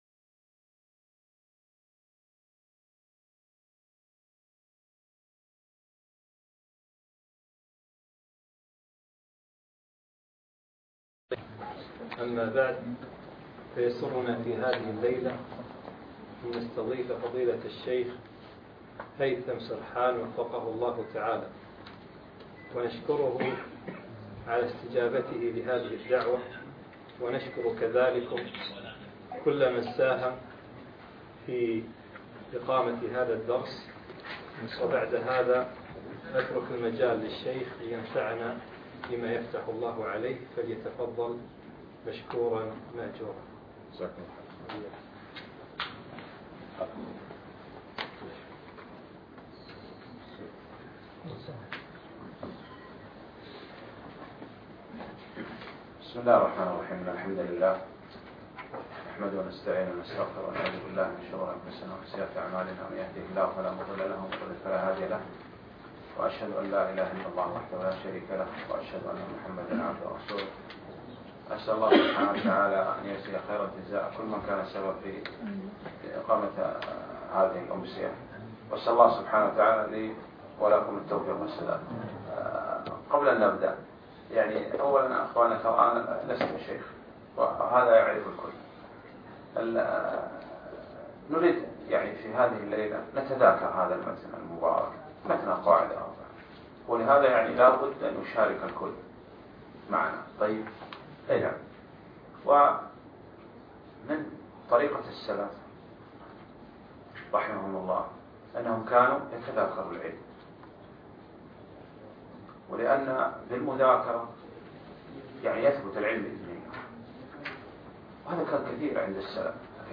القواعد الأربع للإمام محمد بن عبدالوهاب أقيم الدرس بمنطقة سعد العبدالله مشروع الدين الخالص